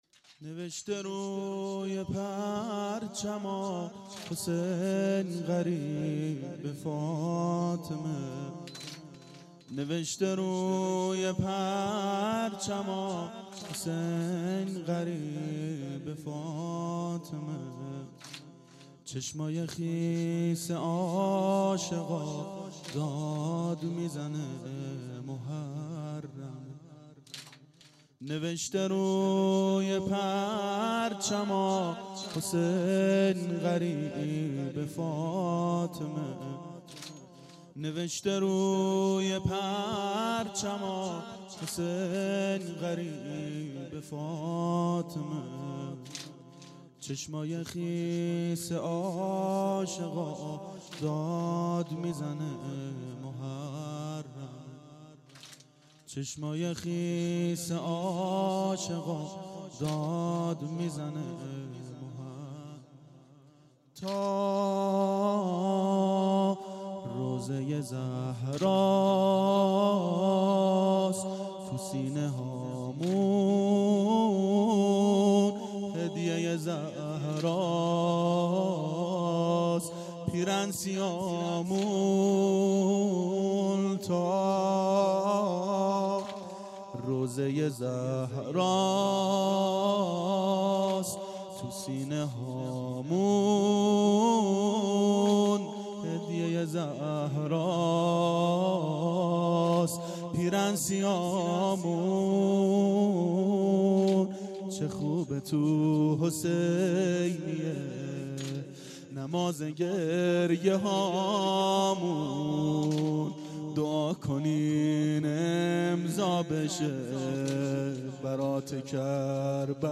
شب اول محرم 92 هیأت عاشقان اباالفضل علیه السلام منارجنبان